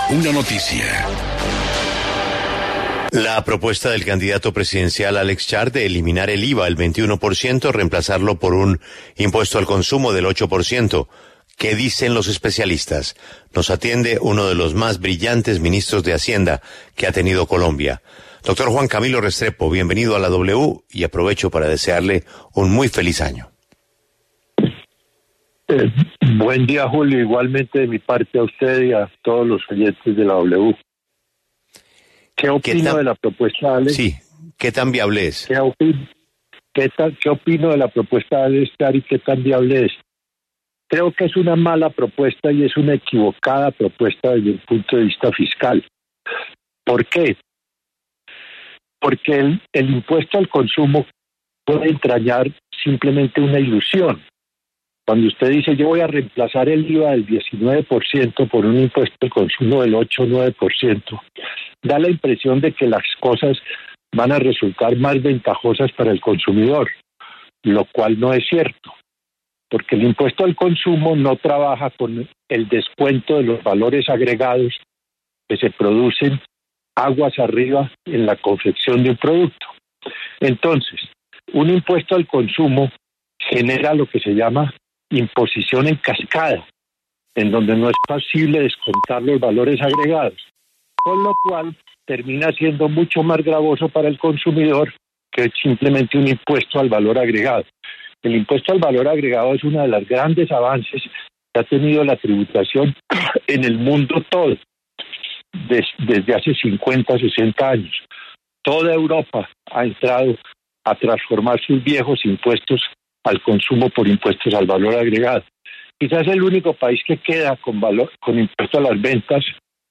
Entrevista exministro Juan Camilo Restrepo en La W.